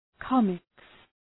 Shkrimi fonetik {‘kɒmıks}